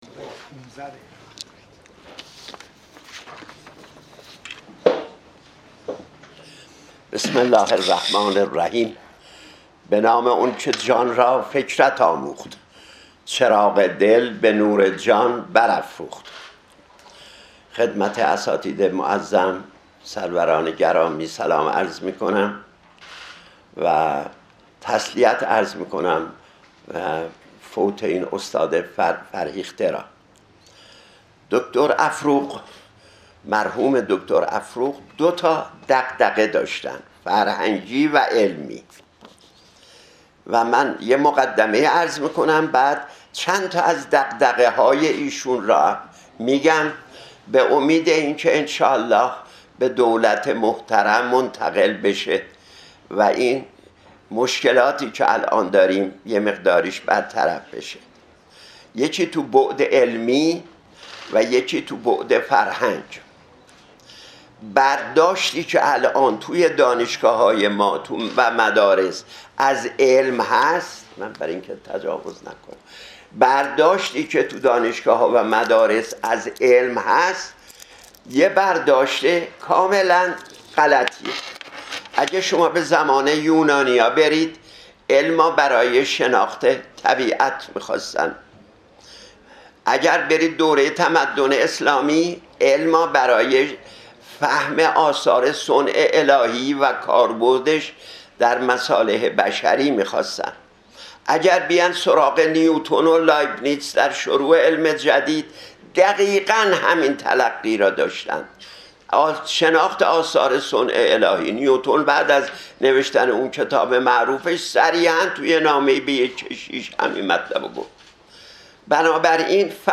مراسم هفتمین روز درگذشت استاد افروغ، در پژوهشگاه علوم انسانی و مطالعات فرهنگی برگزار شد / ۳۰ فروردین ماه/۱۴۰۲